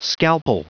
Prononciation du mot scalpel en anglais (fichier audio)
Prononciation du mot : scalpel